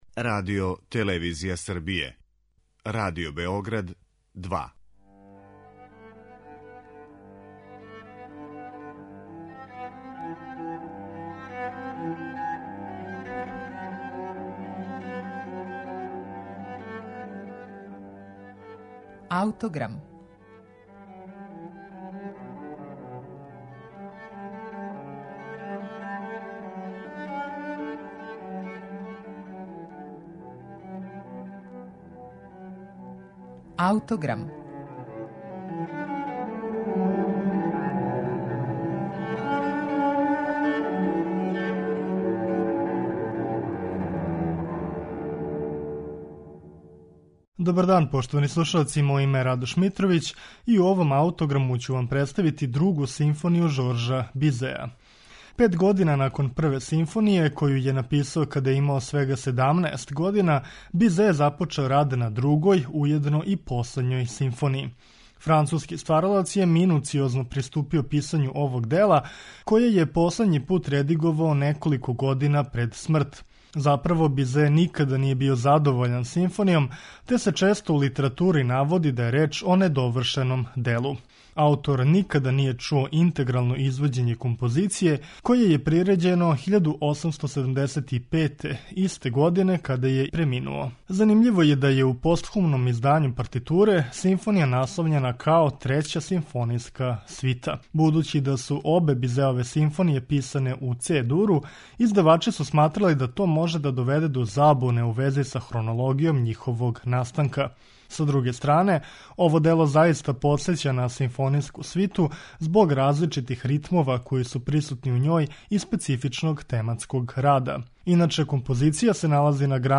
Другу симфонију Жоржа Бизеа слушаћете у извођењу Московског симфонијског оркестра, под управом Веронике Дударове.